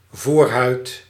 Ääntäminen
Synonyymit preputium Ääntäminen Netherlands: IPA: /ˈvoːrɦœy̯t/ BE-nl: IPA: /ˈvoːrɦœːt/ Haettu sana löytyi näillä lähdekielillä: hollanti Käännös 1. prepúcio {m} Suku: f .